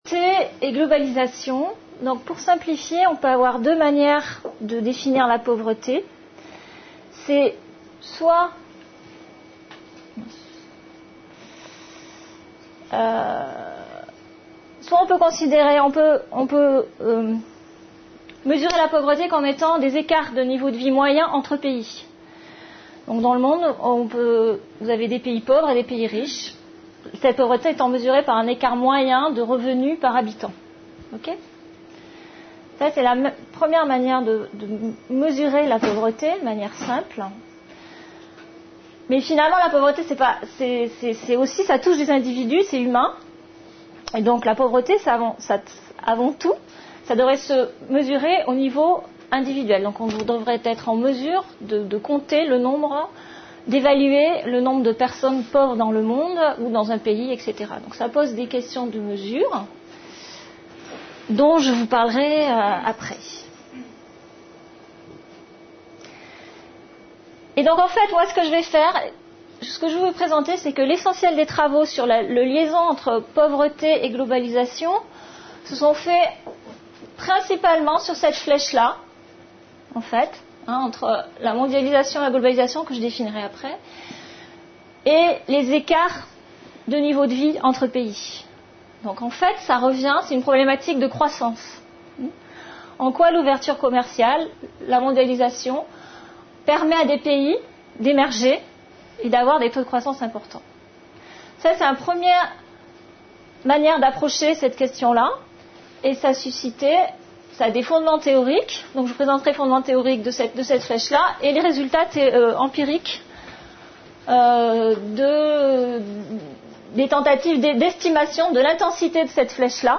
Une conférence de l'UTLS au Lycée